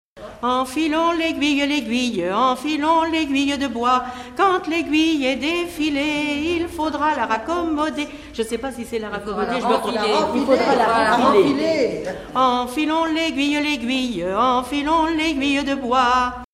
Mémoires et Patrimoines vivants - RaddO est une base de données d'archives iconographiques et sonores.
enfantine : lettrée d'école
gestuel : danse
Pièce musicale inédite